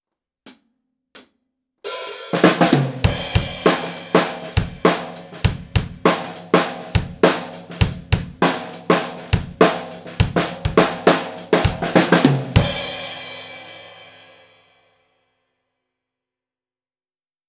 AKG C451B一本のみでドラムを録ってみました。
②ドラム目の前
高さ的には、バスドラムとタムの間ぐらいですね！
各音がはっきりとしてきましたね！